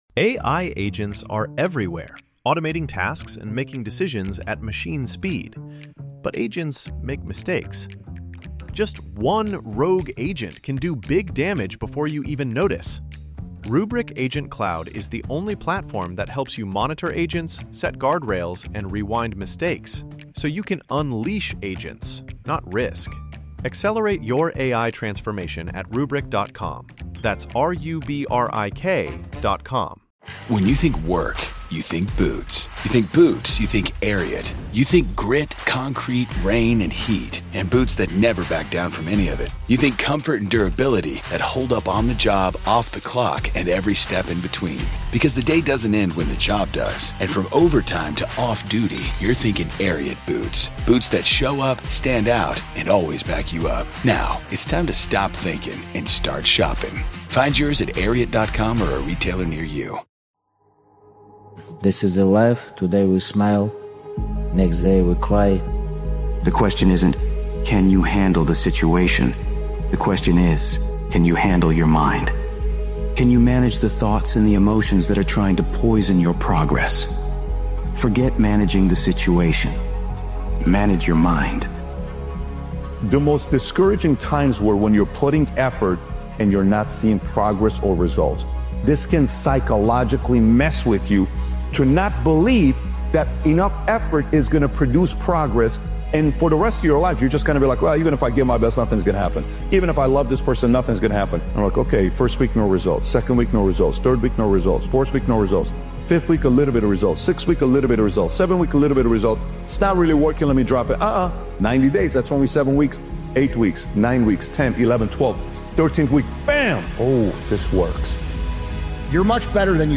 This powerful motivational speeches compilation is a wake-up call to reconnect with your vision—the one you swore you’d chase no matter what.